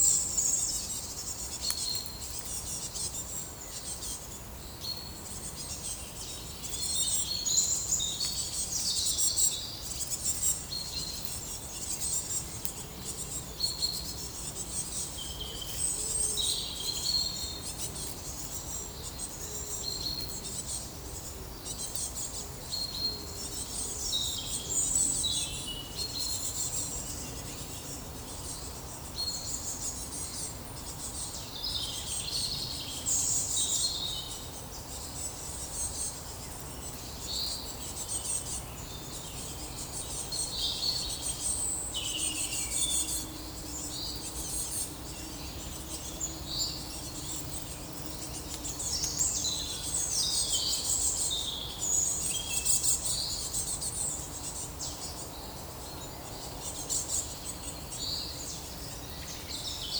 Monitor PAM - Renecofor
Poecile palustris
Erithacus rubecula
Fringilla coelebs
Periparus ater
Cyanistes caeruleus
Certhia brachydactyla
Parus major
Coccothraustes coccothraustes